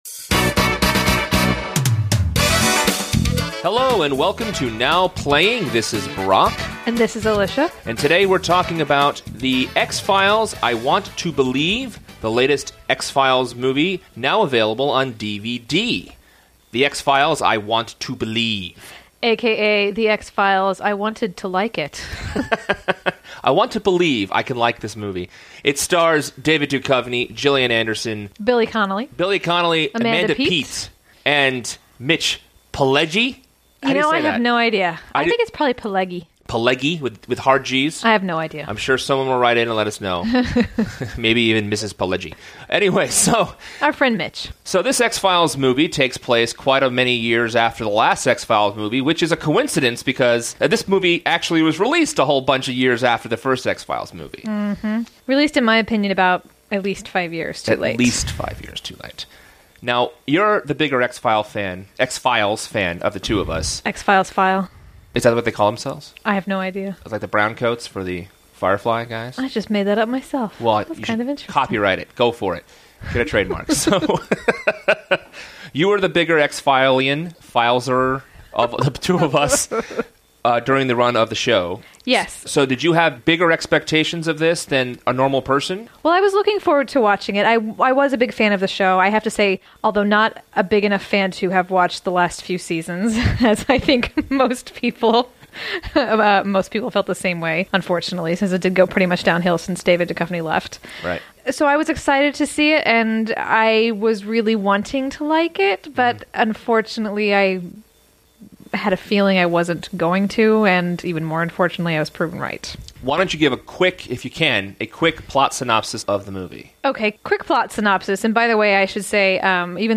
This was an early Now Playing review, shorter and spoiler-free.